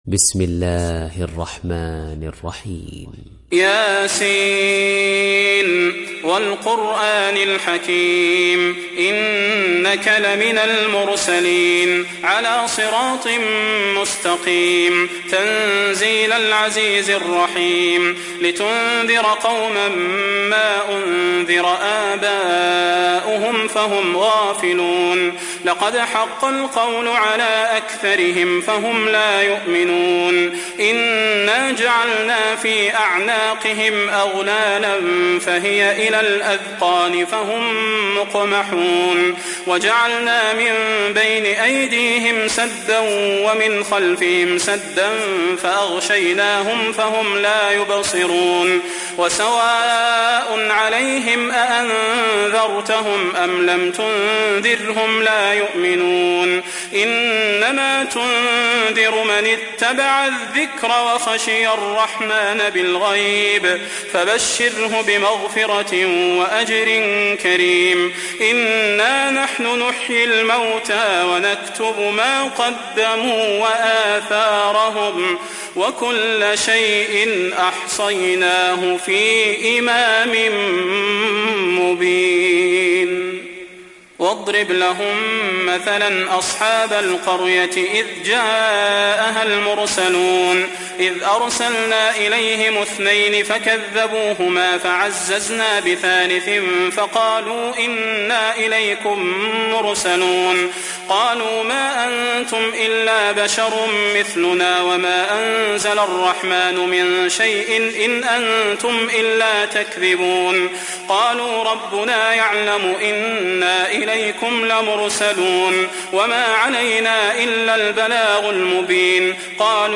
Surat Yasin mp3 Download Salah Al Budair (Riwayat Hafs)